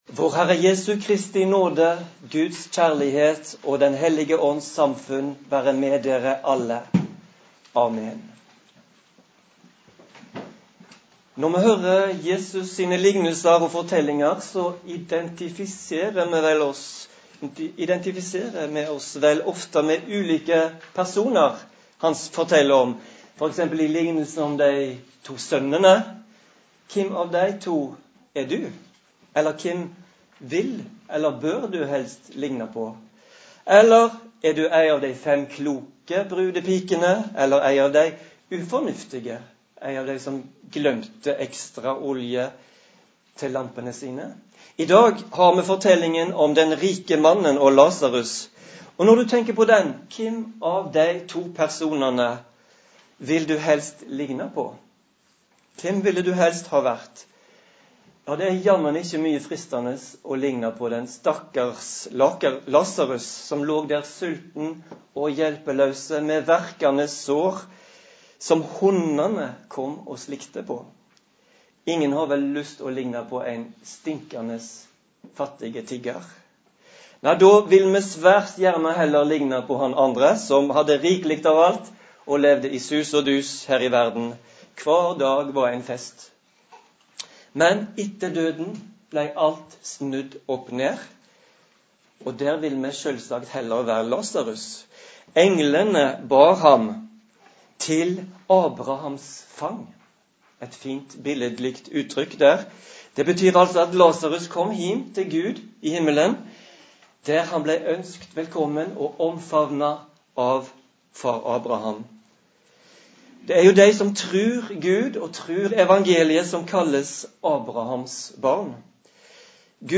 Preken på 1. søndag etter Treenighetsdag
Preika-3-juni.mp3